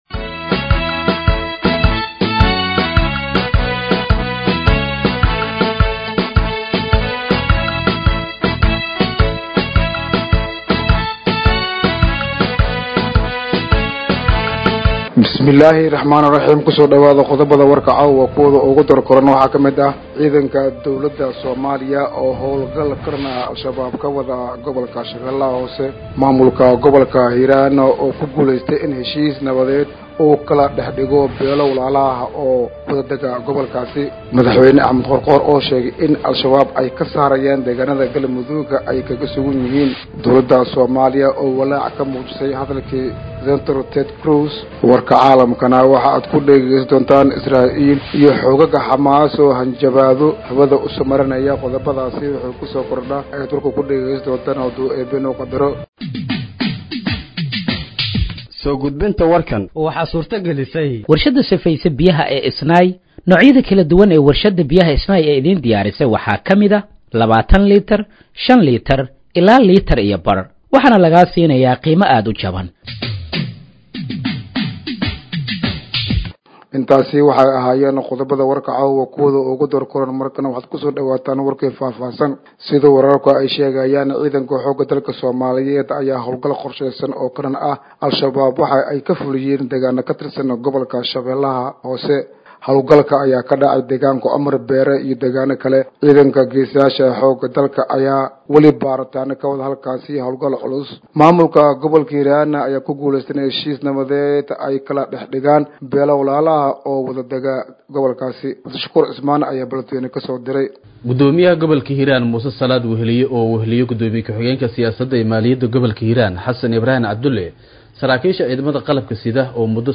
Dhageeyso Warka Habeenimo ee Radiojowhar 15/08/2025
Halkaan Hoose ka Dhageeyso Warka Habeenimo ee Radiojowhar